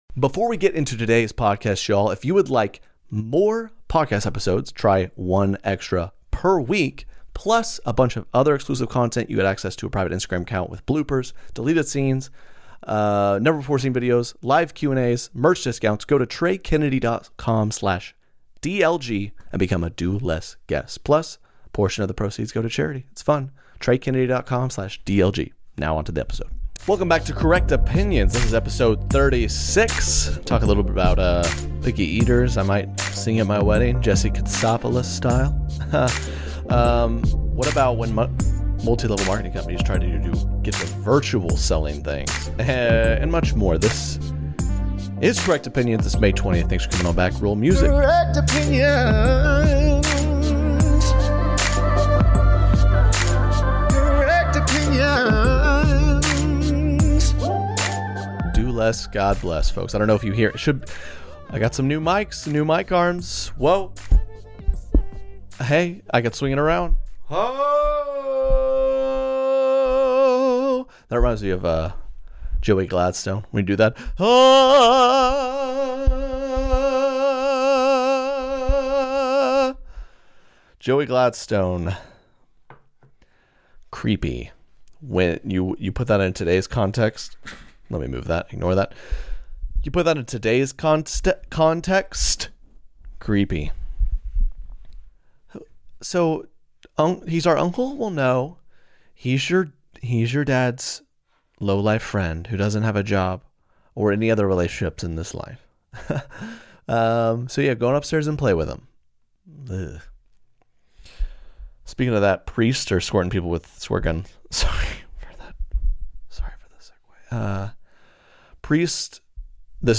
This time he's offering his thoughts on extra people during Covid, MLM's and picky eaters. Also included are his best Uncle Jesse and Nic Cage impressions.